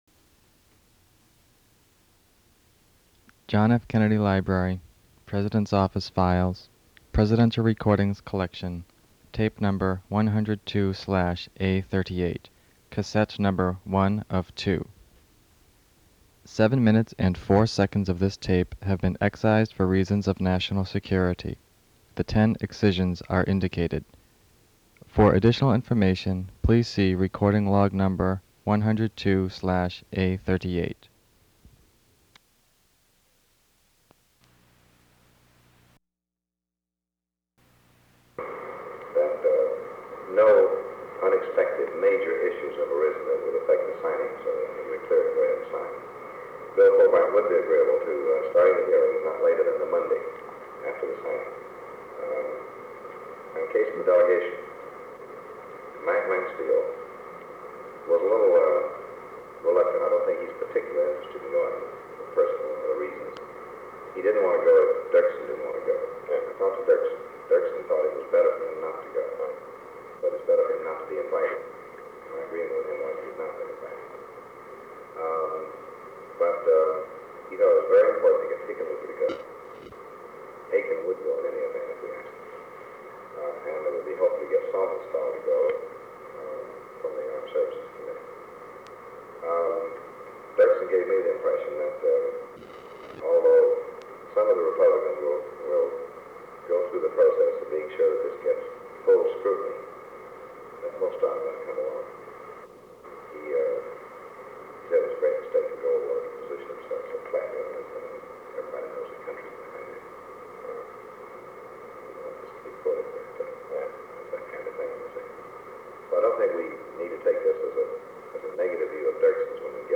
Sound recording of a meeting held on July 29, 1963, between President John F. Kennedy and Secretary of State Dean Rusk. They discuss plans for the signing of the treaty banning atmospheric nuclear weapons tests, later known as the Partial Test Ban Treaty (PTBT) or the Limited Test Ban Treaty (LTBT), letters to congressmen supporting the treaty, and French President Charles de Gaulle’s speech and position on the treaty.
Secret White House Tapes | John F. Kennedy Presidency Meetings: Tape 102/A38.